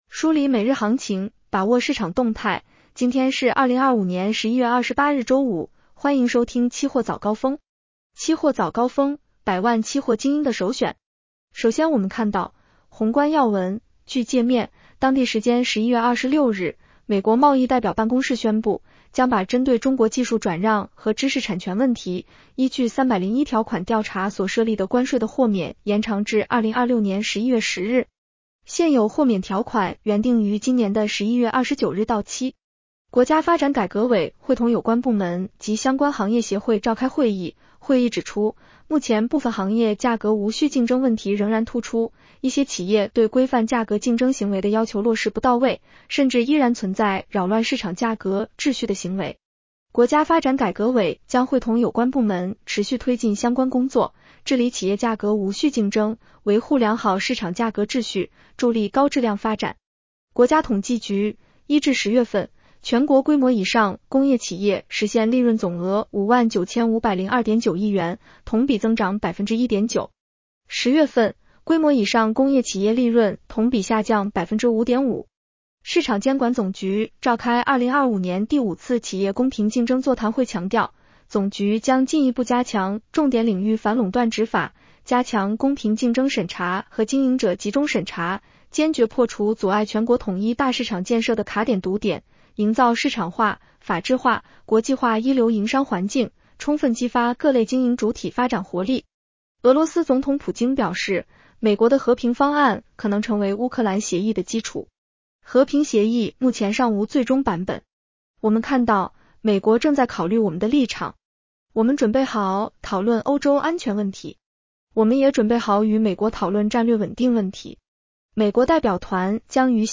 期货早高峰-音频版
期货早高峰-音频版 女声普通话版 下载mp3 热点导读 1.泰国橡胶管理局：洪灾或导致泰国橡胶产量减少多达9万吨。